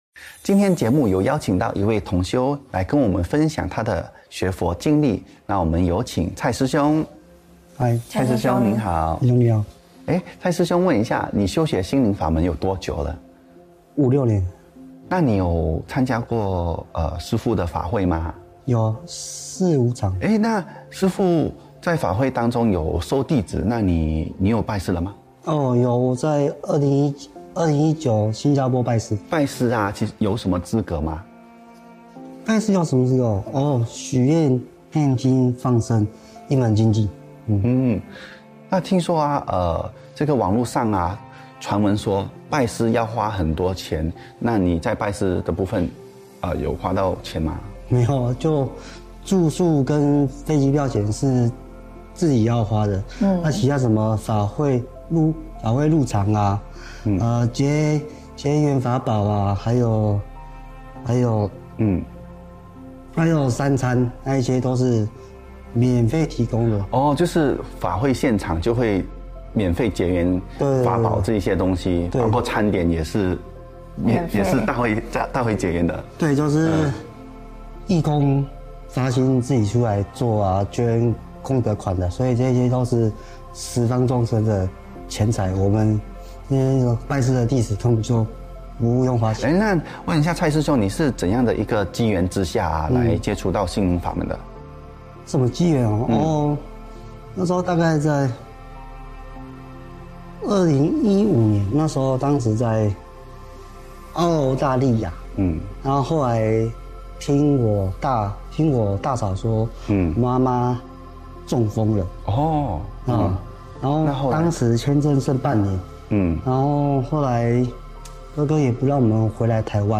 首页 >>弘法视频 >> 新闻报道